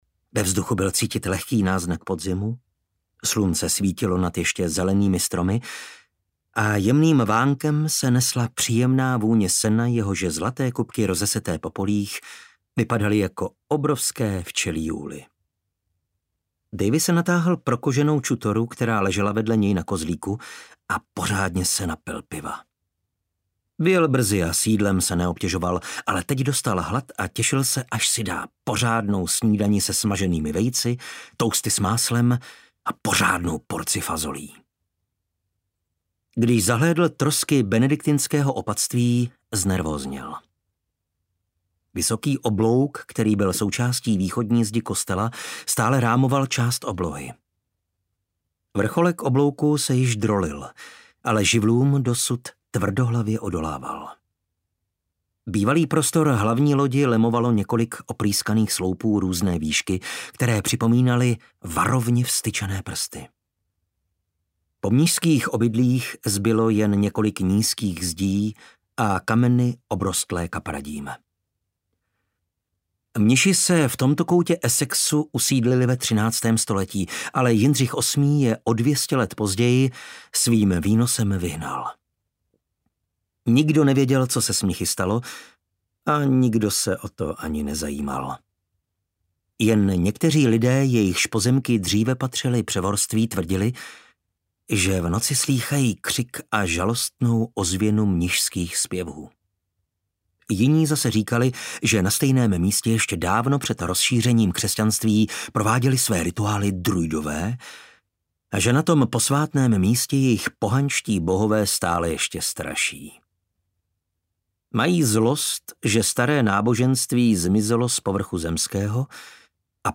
Vražda v opatství audiokniha
Ukázka z knihy